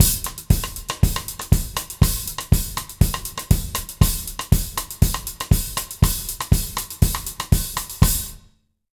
120ZOUK 02-R.wav